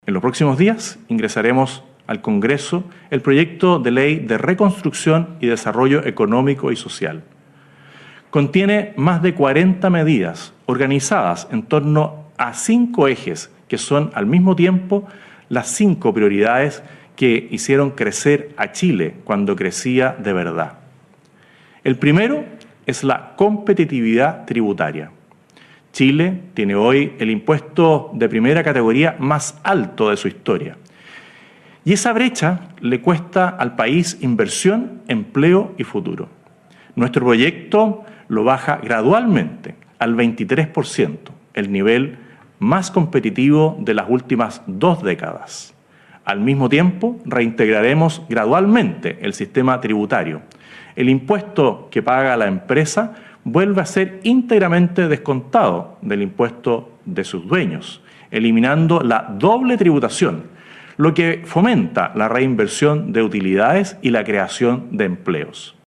En su primera cadena nacional, el Presidente José Antonio Kast realizó un balance de su primer mes de gobierno y anunció el envío al Congreso del proyecto de ley de Reconstrucción y Desarrollo Económico y Social para “impulsar el crecimiento, el empleo y la seguridad en el país”.
cuna-01-cadena-nacional-Kast.mp3